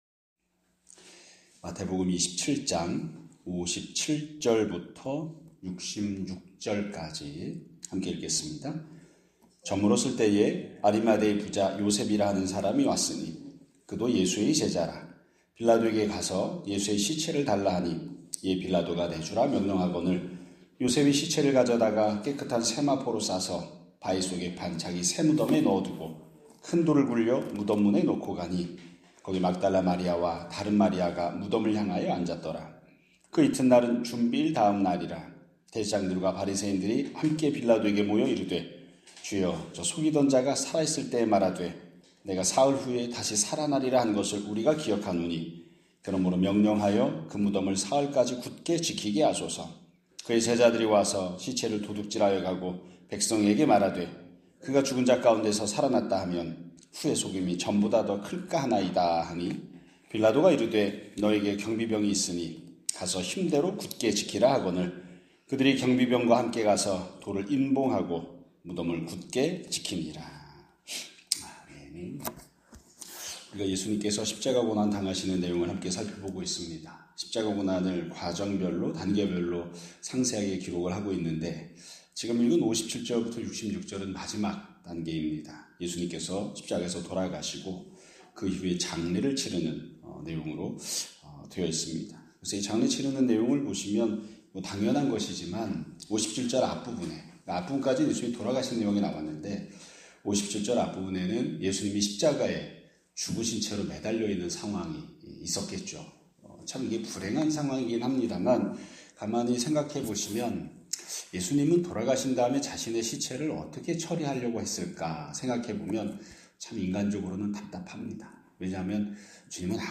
2026년 4월 24일 (금요일) <아침예배> 설교입니다.